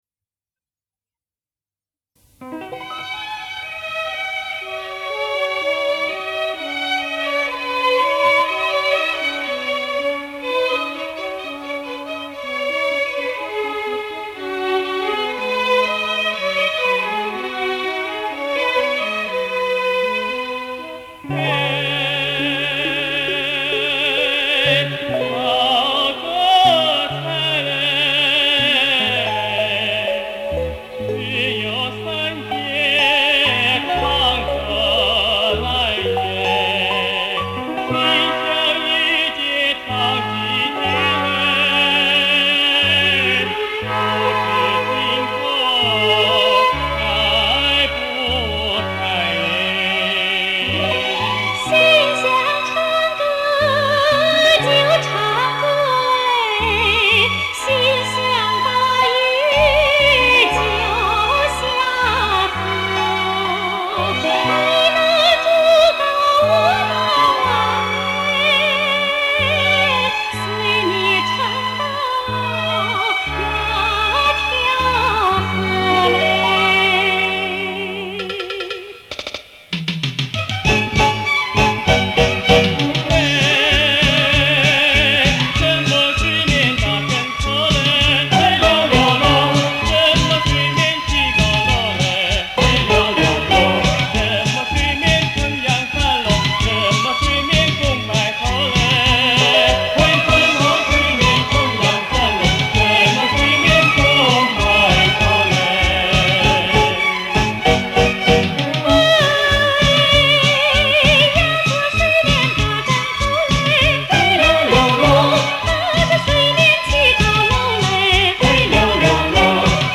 广西民歌